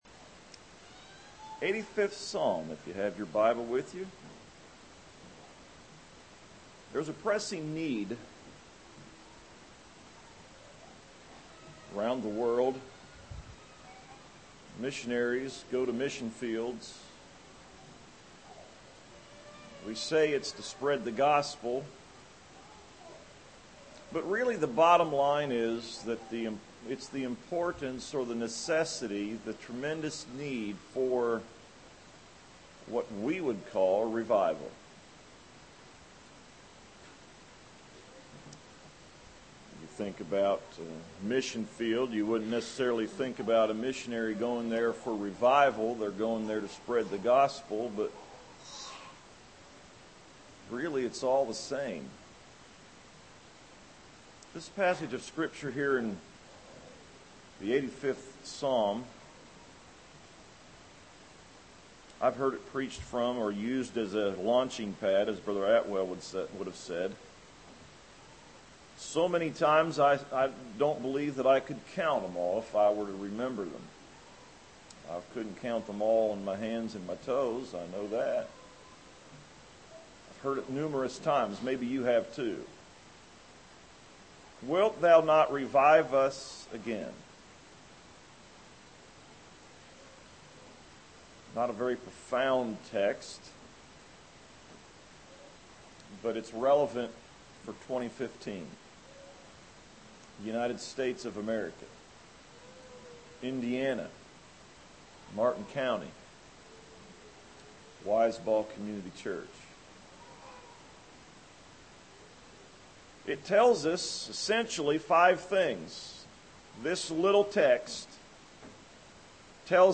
A message from Psalm 85:6 on the need for revival, the source of revival, the means of revival, the subjects for revival, and the effects of revival.